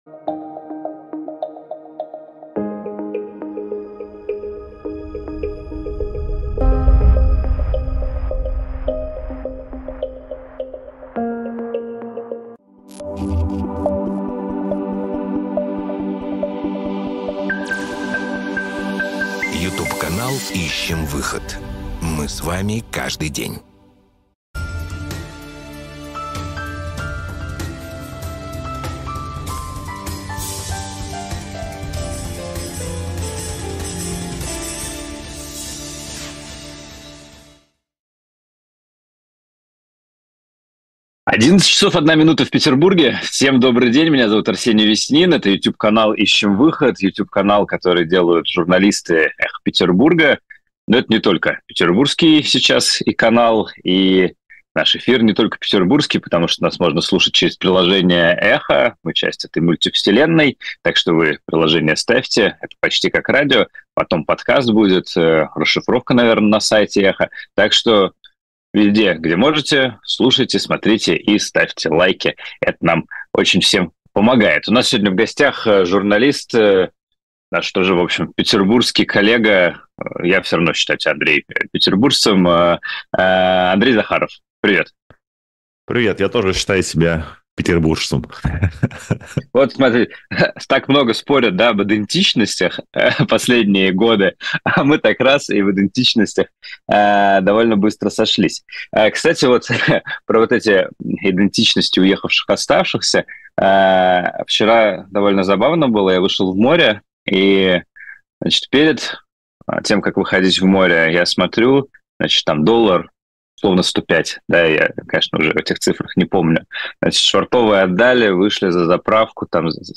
журналист-расследователь